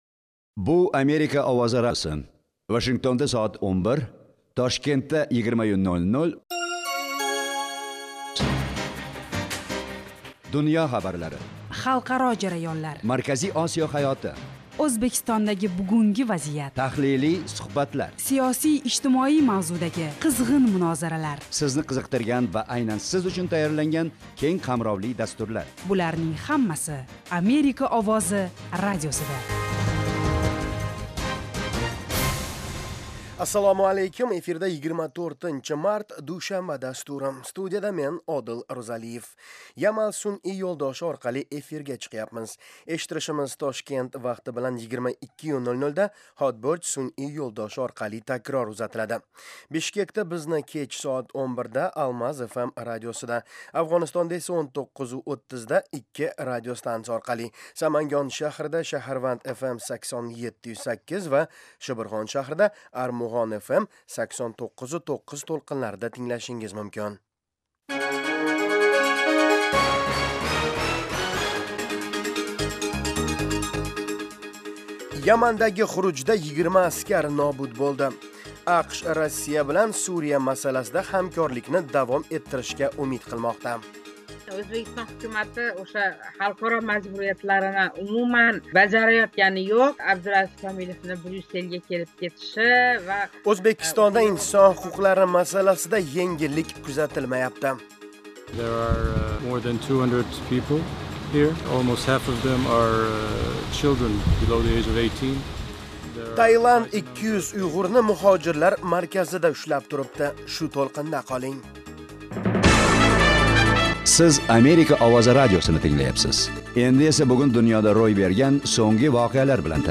Toshkent vaqti bilan har kuni 20:00 da efirga uzatiladigan 30 daqiqali radio dastur kunning dolzarb mavzularini yoritadi. O'zbekiston va butun Markaziy Osiyodagi o'zgarishlarni tahlil qiladi. Amerika bilan aloqalar hamda bu davlat siyosati va hayot haqida hikoya qiladi.